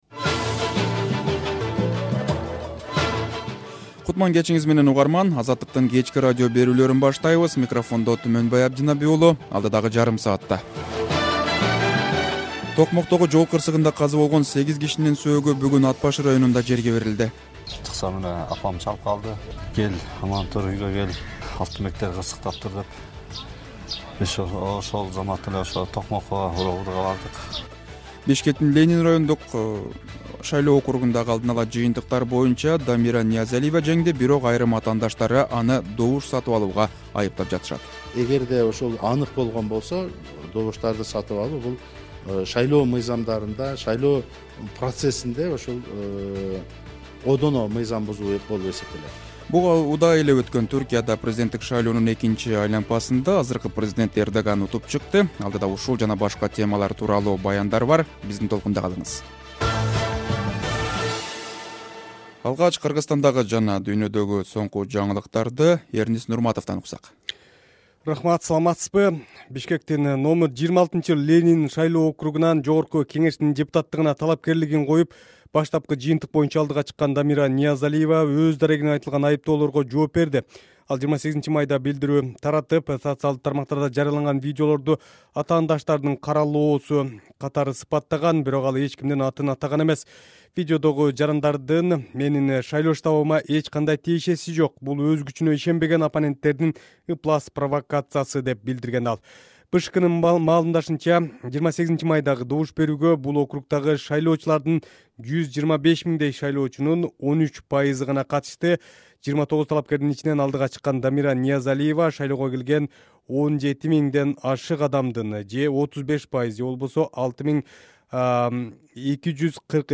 Кечки радио берүү | 29.05.2023 | Академиянын профессорунун “улуттук крокодил” деген сөзү талкууда